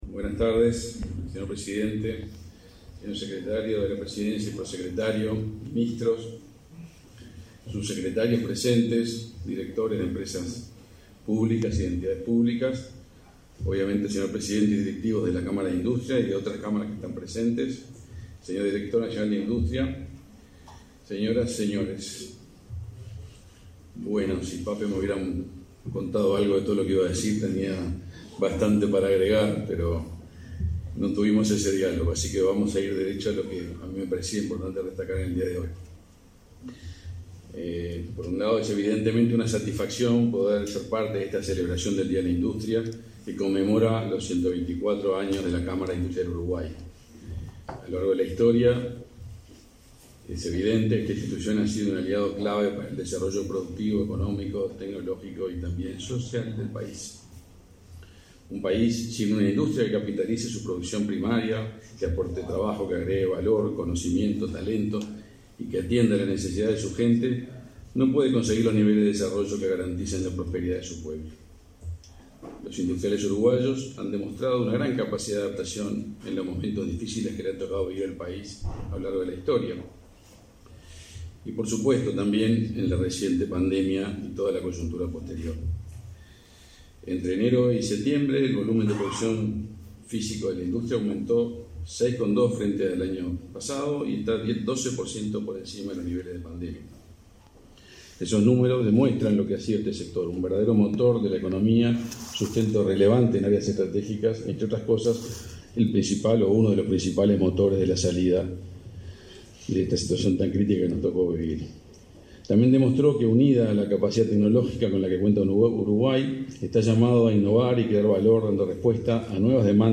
Palabras del ministro del MIEM, Omar Paganini
Con la presencia del presidente de la República, Luis Lacalle Pou, se realizó, este 14 de noviembre, la celebración del Día de la Industria.